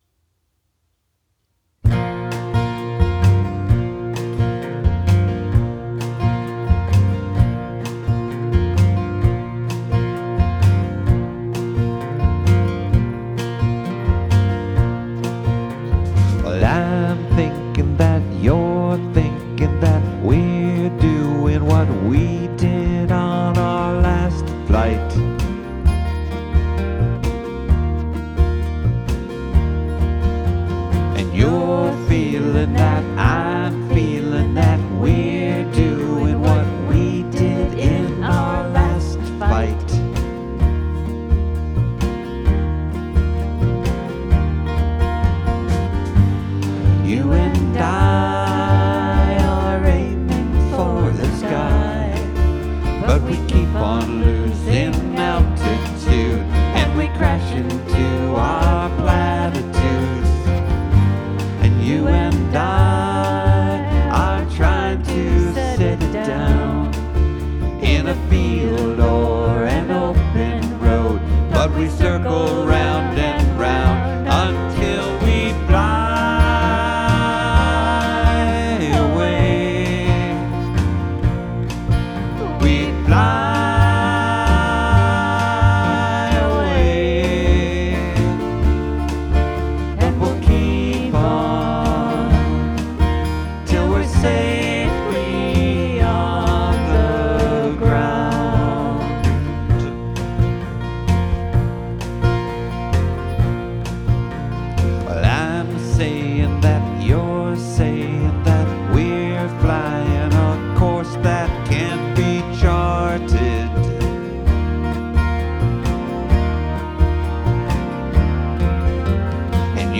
Americana · Folk · Pop
American roots folk/pop music duo